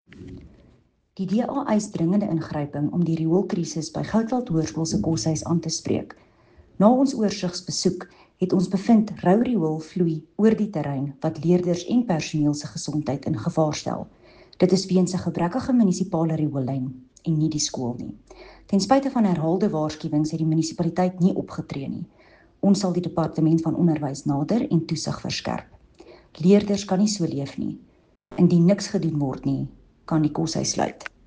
Afrikaans soundbites by Cllr René Steyn and